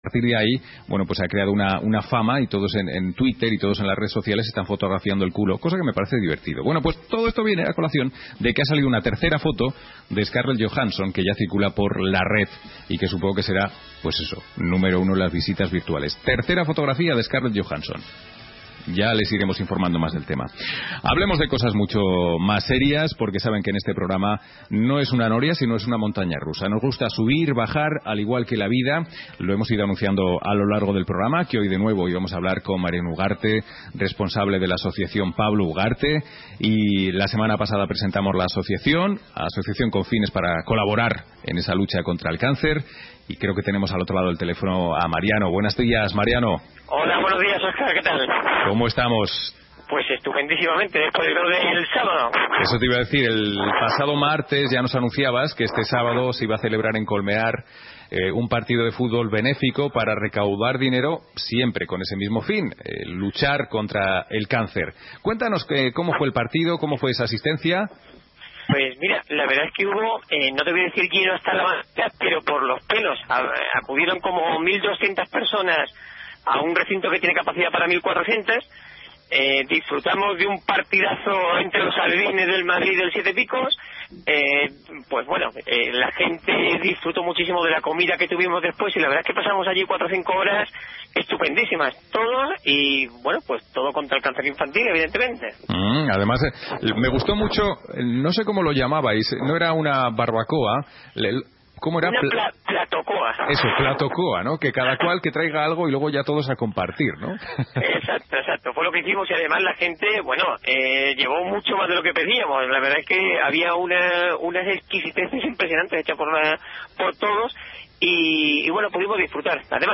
- Memoria I Memorial Fútbol - Entrevista "Memorial" - Nota de Prensa "La Razón" - Cartel anunciador